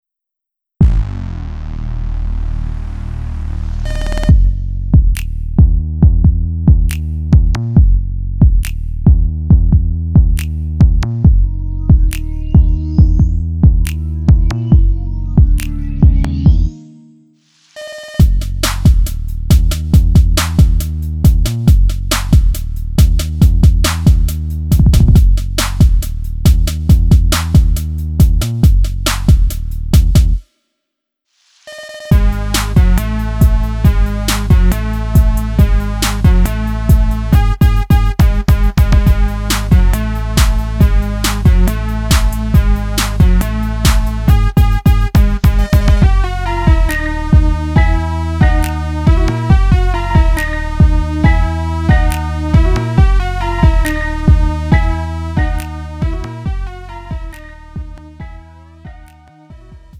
음정 원키 2:40
장르 가요 구분